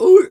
seal_walrus_2_hurt_03.wav